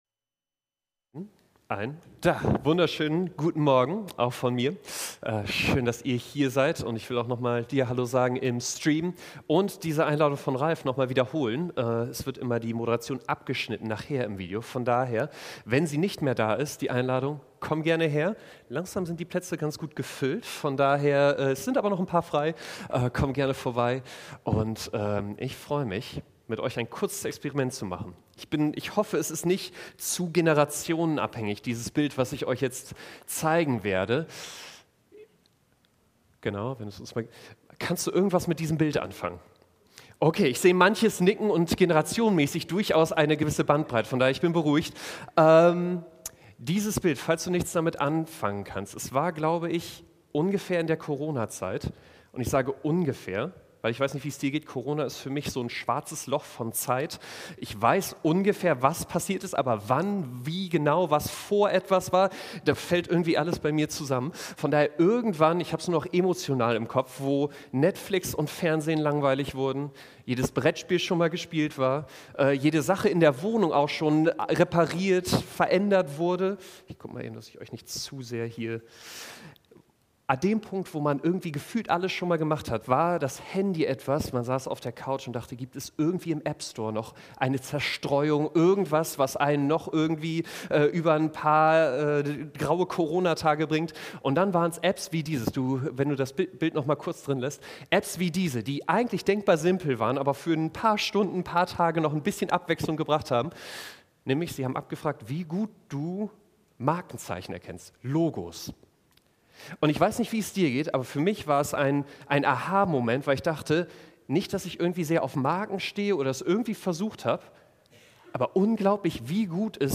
Dateien zum Herunterladen Update Predigt als MP4